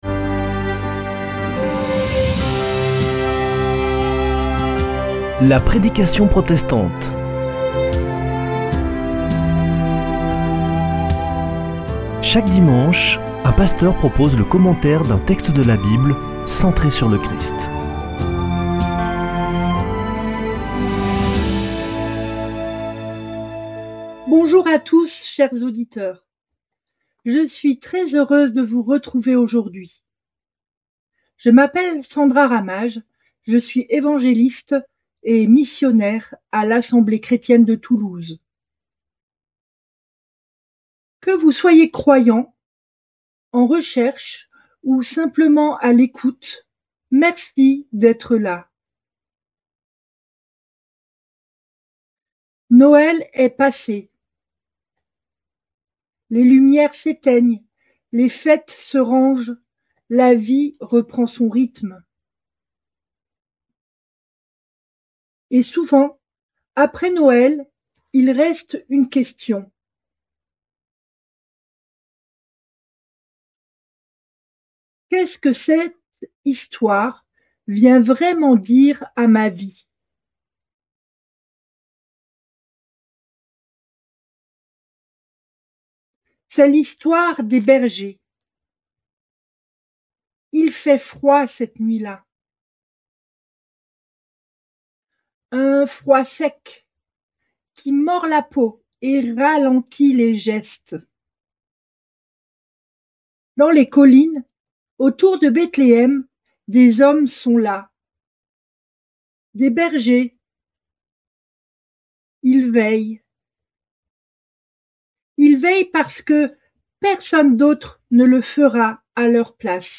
Accueil \ Emissions \ Foi \ Formation \ La prédication protestante \ Quand les bergers rencontrent Jésus : l’histoire de Noël qui touche tous les (…)